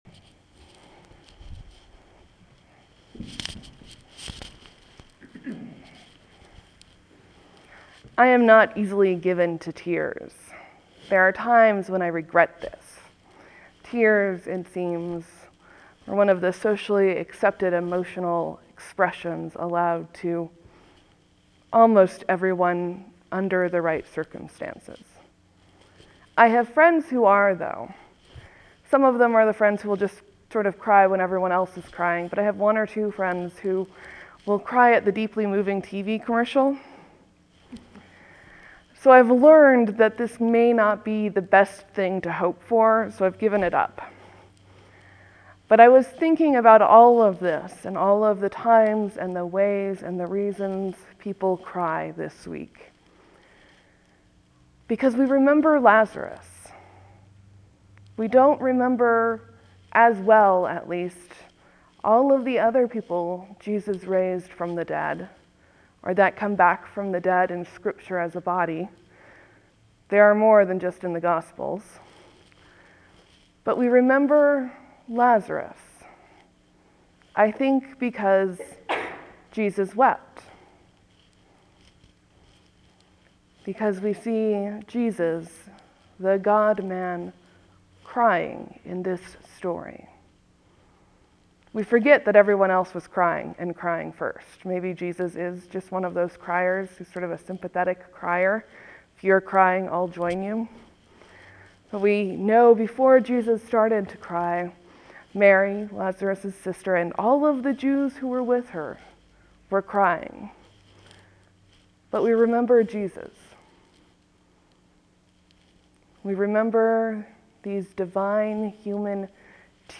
(There will be a few moments of silence before the sermon starts. Thank you for your patience.)